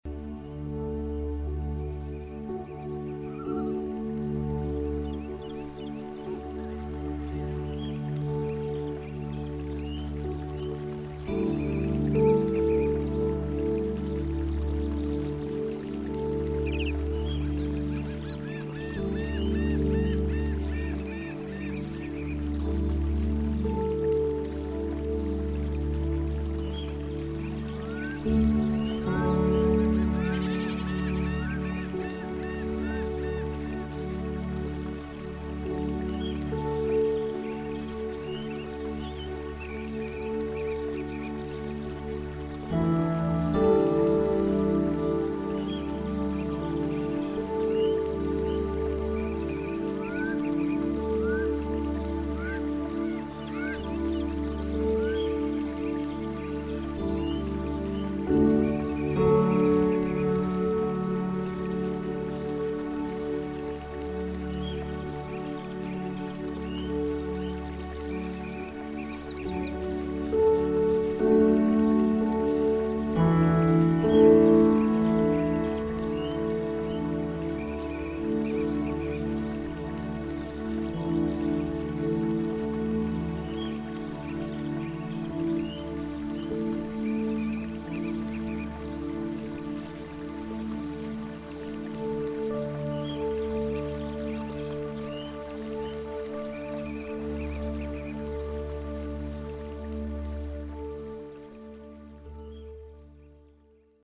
• בזמן ההקשבה להקלטות, לא תשמעו את המסרים עצמם אלא רק מוזיקה או קולות רקע.
דוגמה מהצהרות הסמויות עם מוזיקה וקולות פיקניק בטבע: